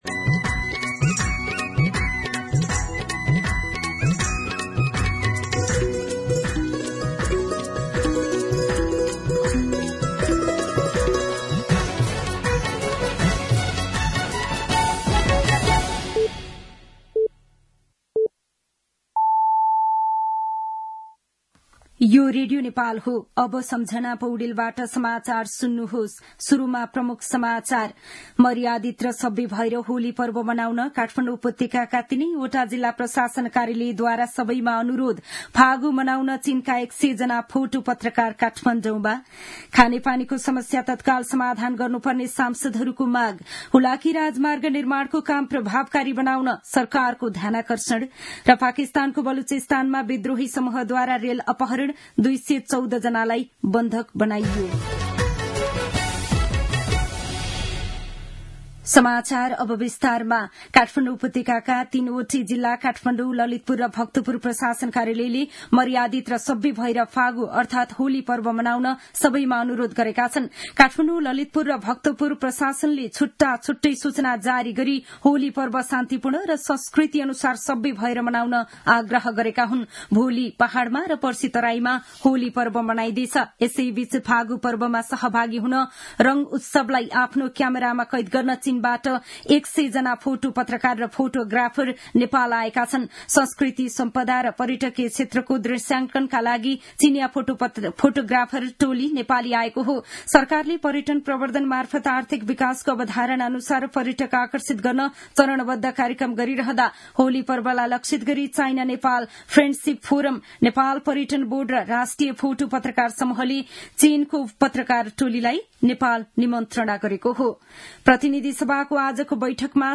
दिउँसो ३ बजेको नेपाली समाचार : २९ फागुन , २०८१
3pm-News-11-28.mp3